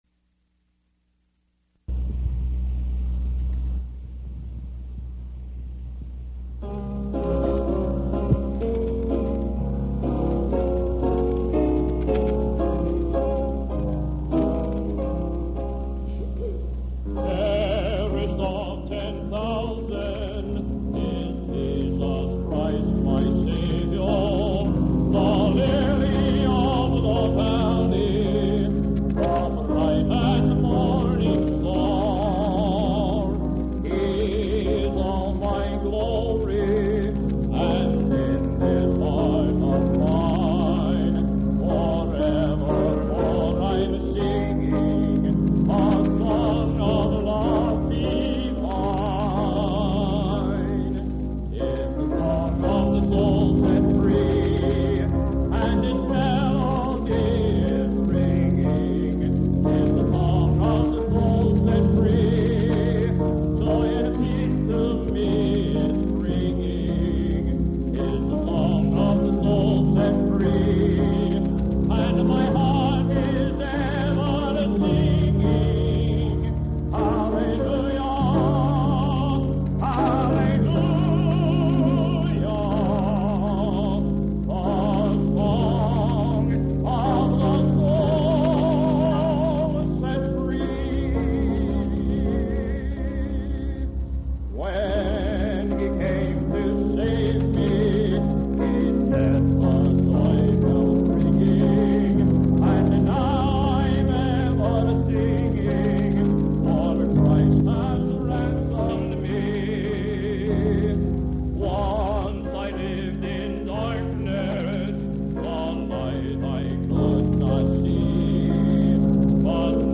Worship Concert Choir